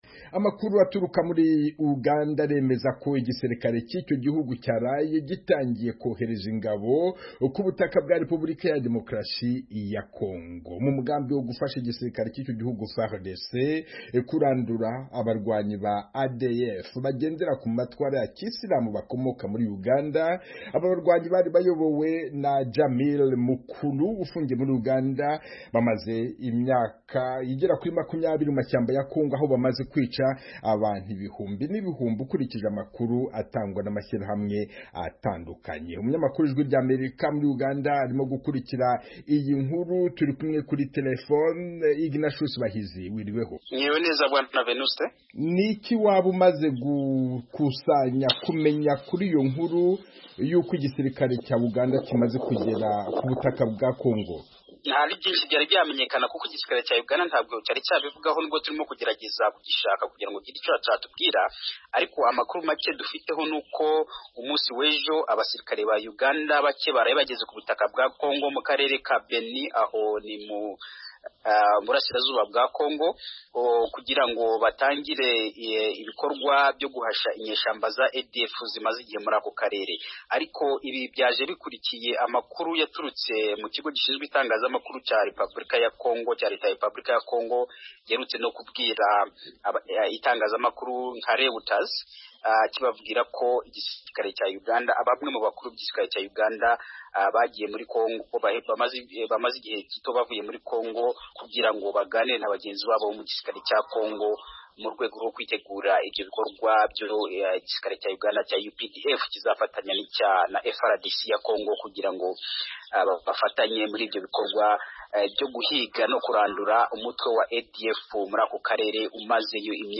Amakuru mu Karere